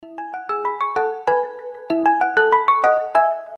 Рингтоны без слов , Короткие рингтоны
Рингтоны на будильник